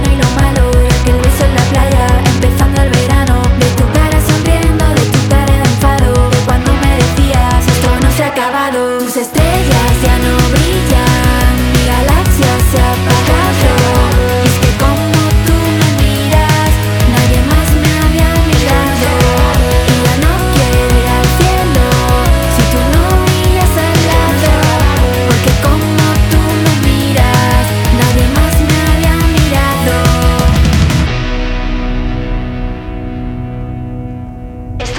Жанр: Рок / Альтернатива
# Rock y Alternativo